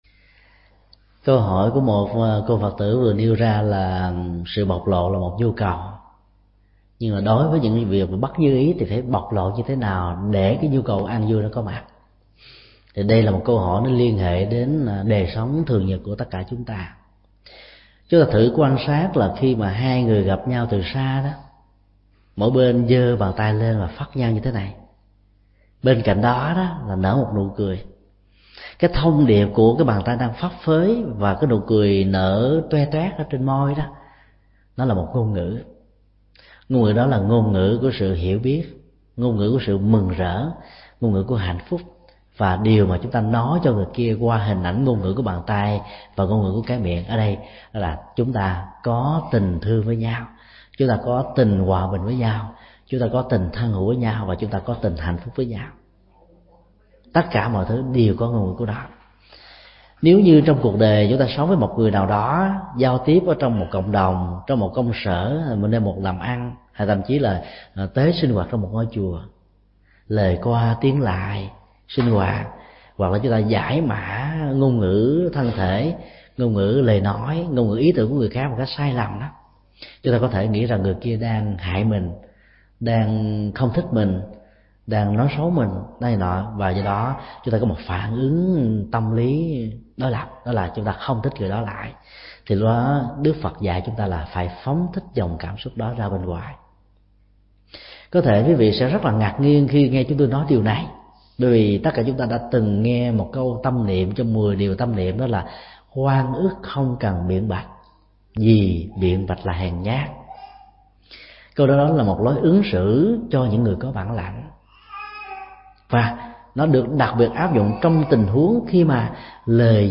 Nghe mp3 Vấn đáp: Nghệ thuật vượt qua nổi khổ niềm đau trong cuộc sống – Thượng Tọa Thích Nhật Từ